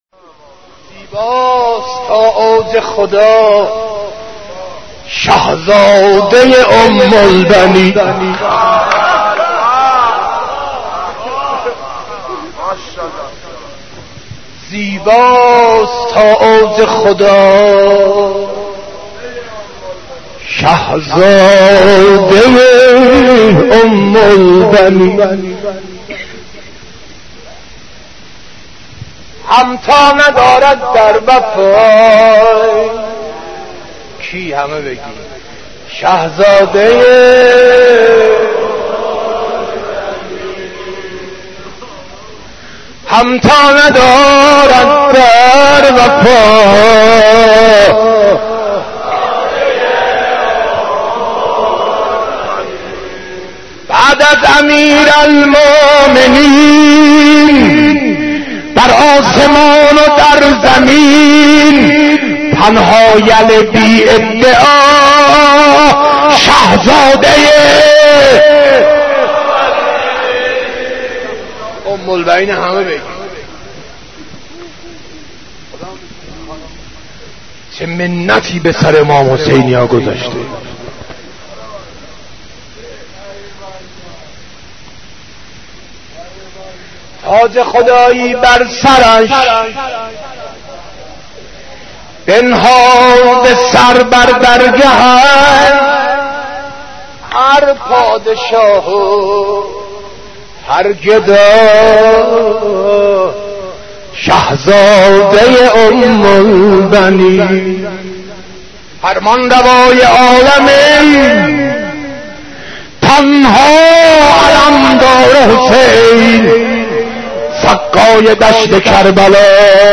حضرت عباس ع ـ مداحی 4